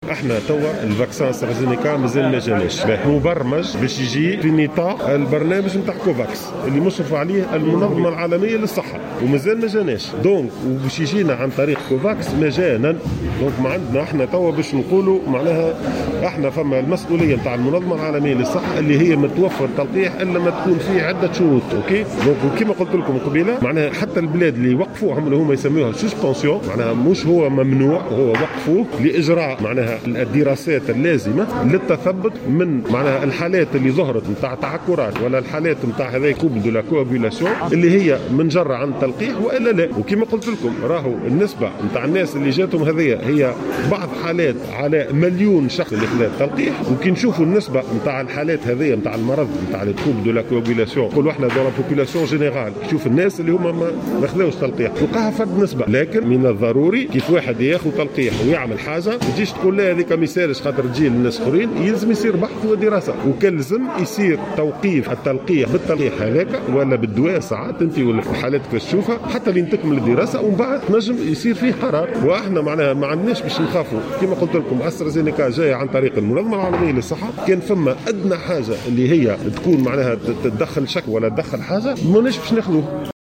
وقال الوزير في تصريح للجوهرة أف أم ، إن حالات التعكّرات التي سبّبها هذا التلقيح قليلة مقارنة بملايين الأشخاص الذين تلقوا التلقيح .